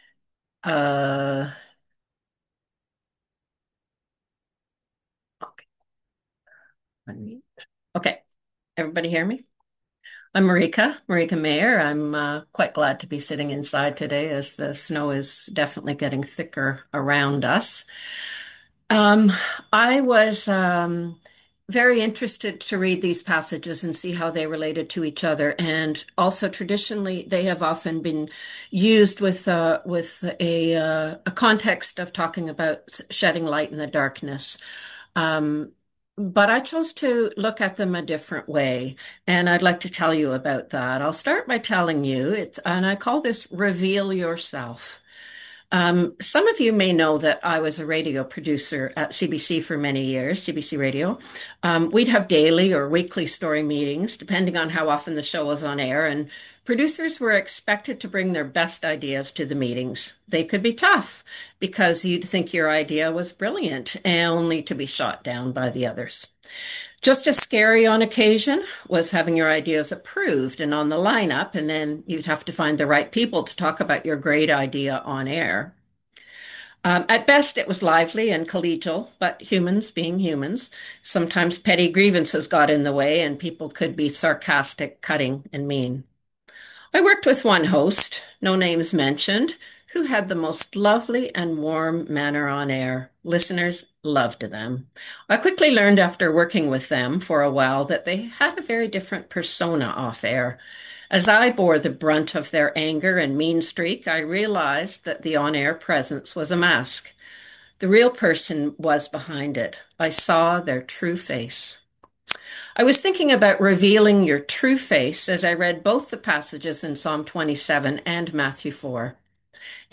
Worship Team
Due to the heavy snowfall and road conditions, TUMC moved its service online for this weekend.